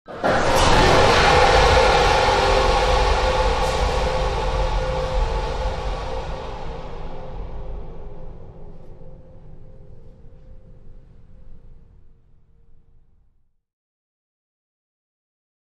Shock Fire; Warning Beeps, Heavy Power Surge And Whoosh With Rumble